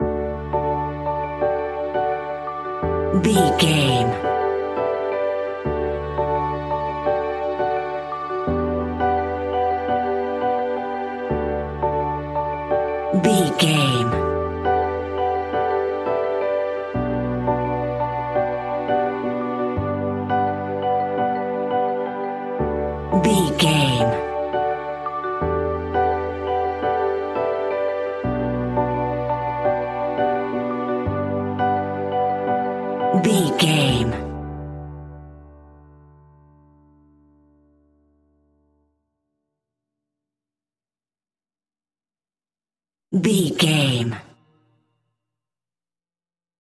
Ionian/Major
pop rock
indie pop
energetic
uplifting
upbeat
groovy
guitars
bass
drums
piano
organ